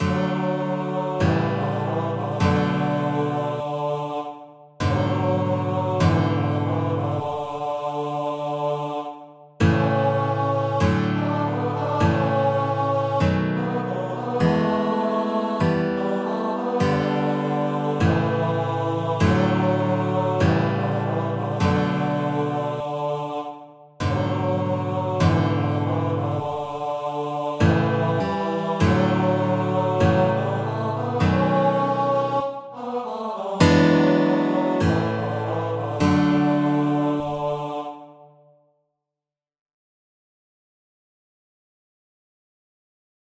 2/4 (View more 2/4 Music)
Jazz (View more Jazz Lead Sheets Music)
Rock and pop (View more Rock and pop Lead Sheets Music)